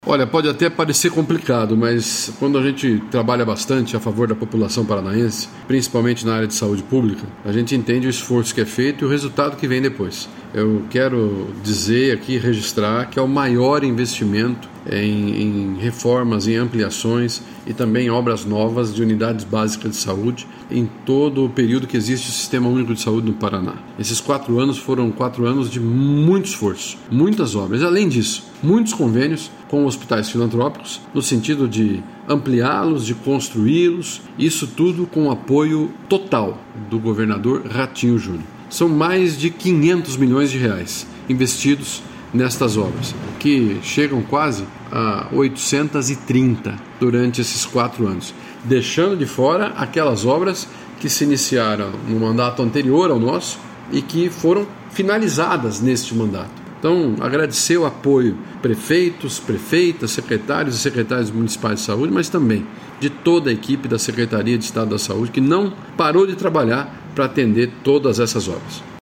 Sonora do secretário da Saúde, Beto Preto, sobre os investimentos realizados nas 22 Regionais de Saúde